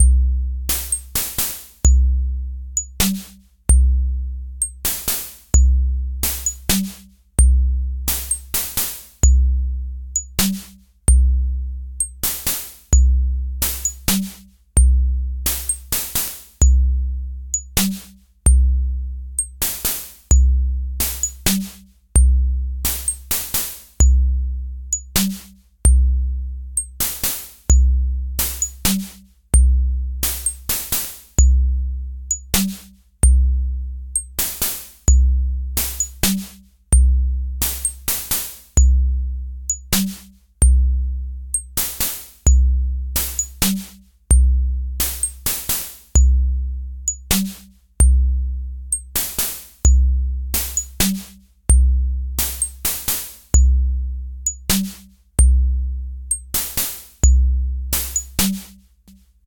Bucle de percusión electrónica
Música electrónica
melodía
repetitivo
sintetizador